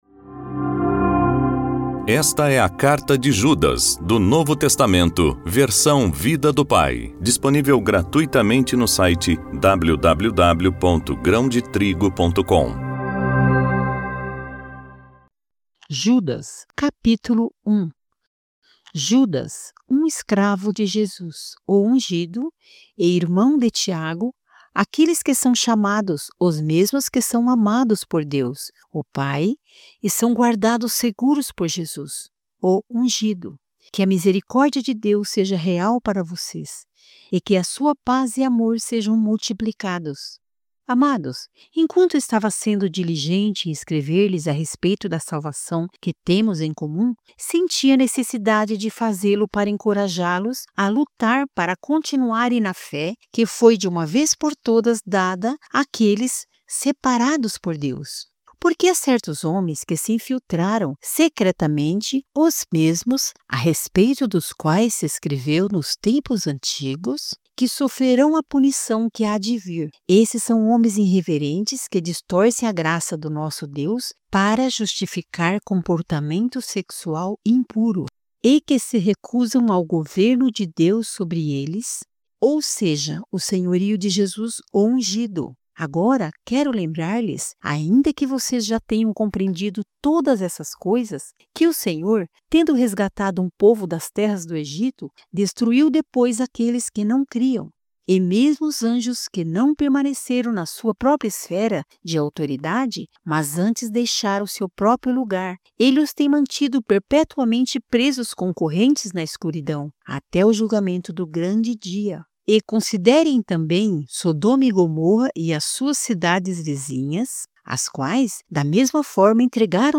voz-audiobook-novo-testamento-vida-do-pai-judas-capitulo-1.mp3